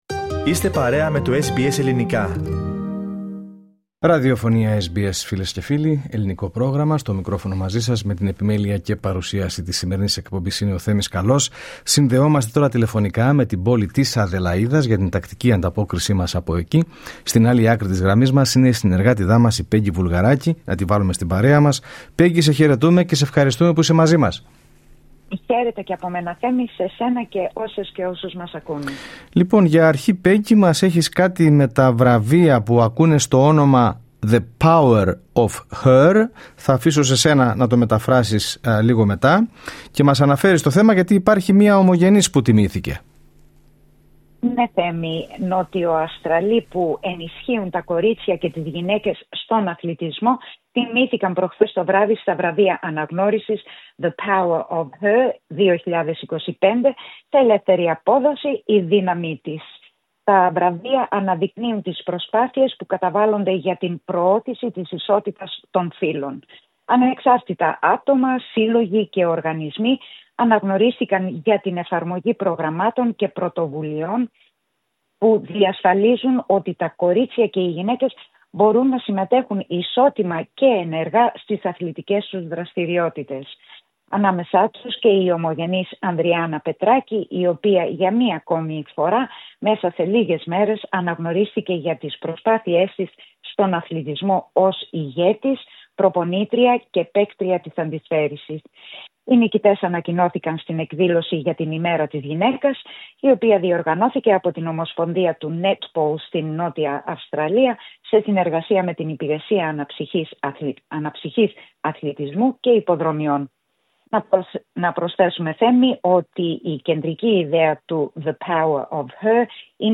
Ακούστε την εβδομαδιαία ανταπόκριση από την Αδελαΐδα